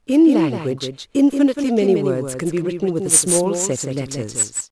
spraak in een semi-anechoïsch veld met d=30 m.
Dit fragment werd achteraf bewerkt om het geluidveld in de omgeving van een reflecterende wand en een galmveld te simuleren.
Speech_echo30m2.wav